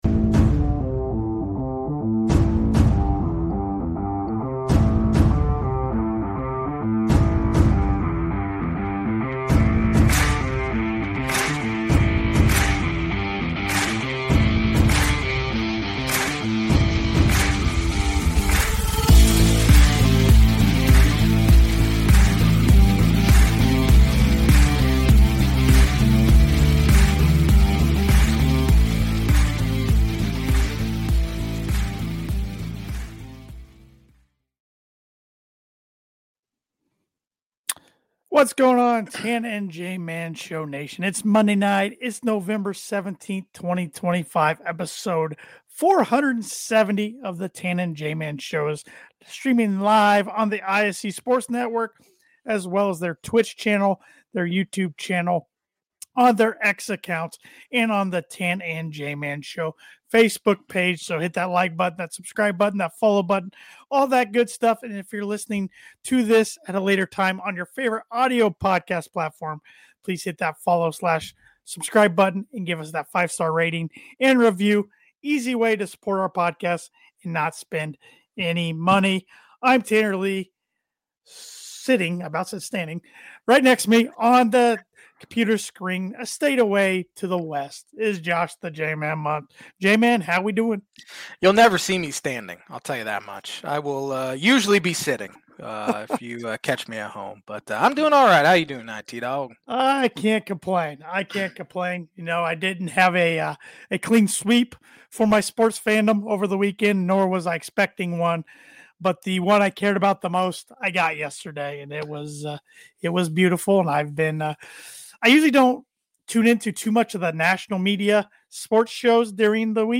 Hot takes, topics, picks and more! A weekly sports podcast hosted by two huge sports fanatics who cover the NFL, Big Ten, MLB and more!